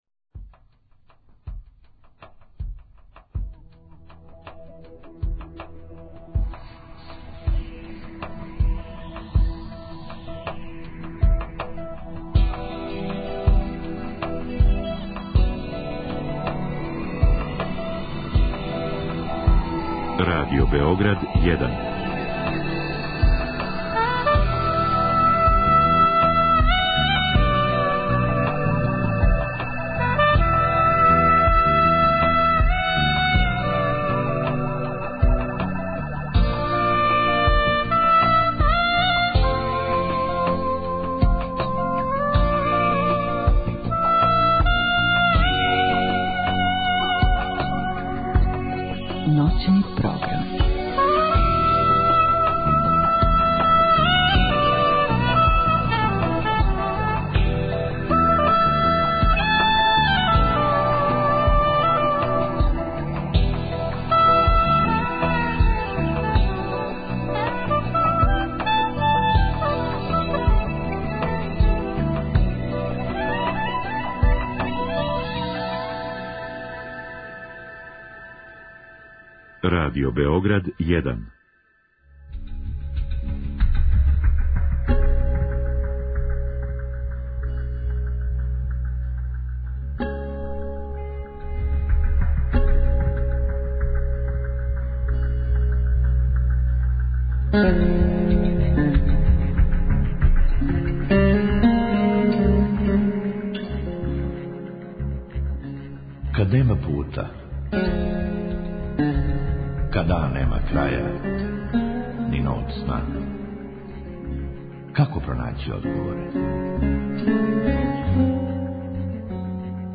У другом сату емисије слушаоци могу директно да се укључе у програм са својим коментарима, предлозима и проблемима који се односе на психолошки свет, међу свим другим световима.